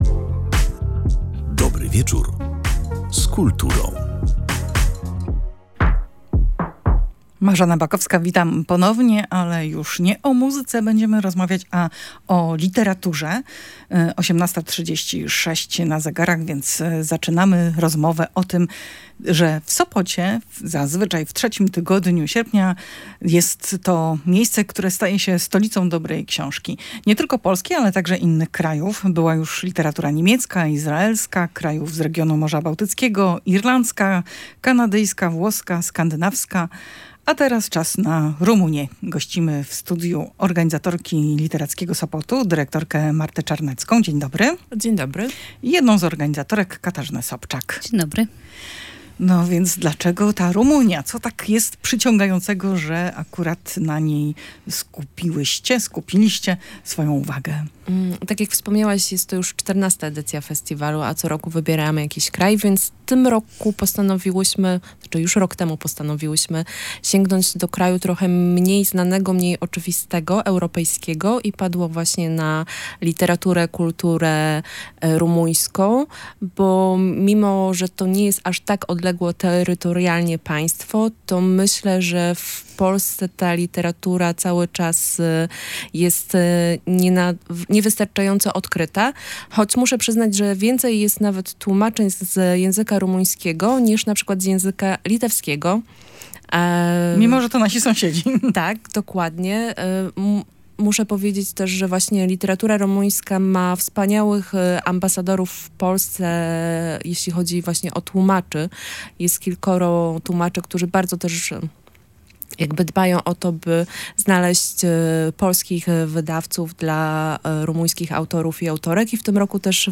w audycji na żywo